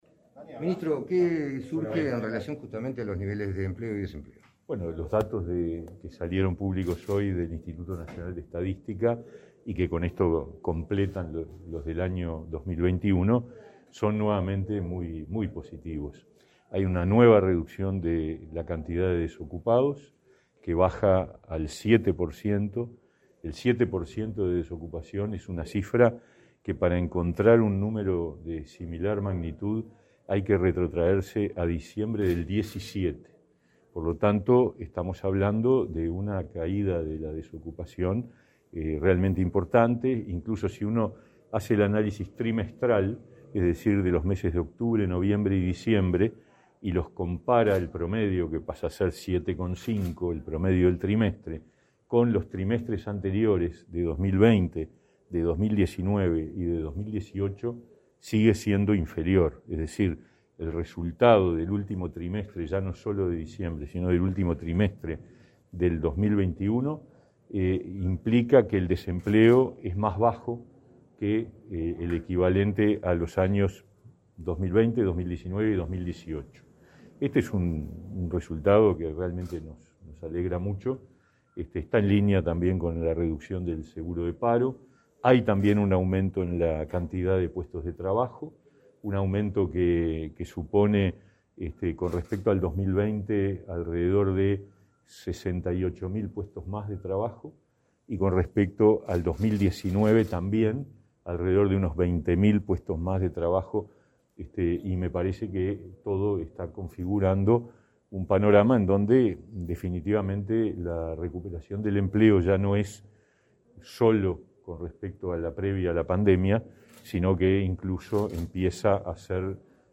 Declaraciones a la prensa del ministro de Trabajo y Seguridad Social, Pablo Mieres
Declaraciones a la prensa del ministro de Trabajo y Seguridad Social, Pablo Mieres 01/02/2022 Compartir Facebook X Copiar enlace WhatsApp LinkedIn En diciembre, la desocupación fue de 7%, y mostró el registro mensual más bajo desde diciembre de 2017, según un informe del Instituto Nacional de Estadística. Sobre el tema, el ministro Mieres efectuó declaraciones a la prensa.